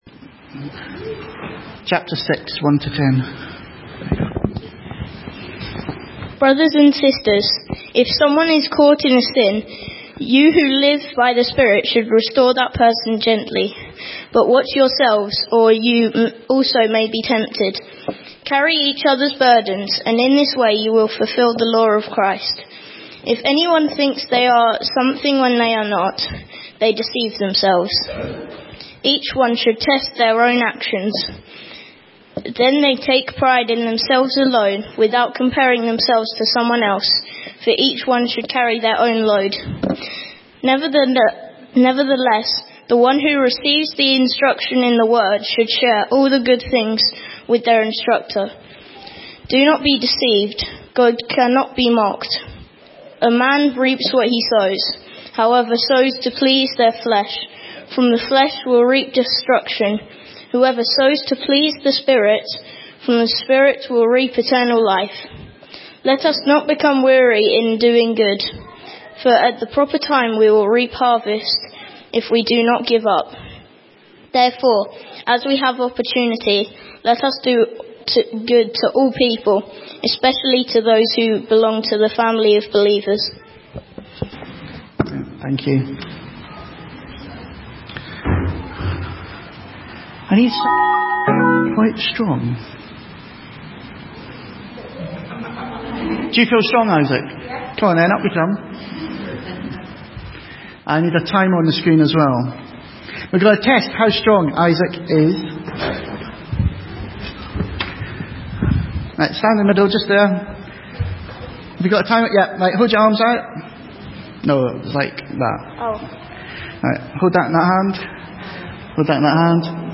A message from the series "Wellbeing Journey."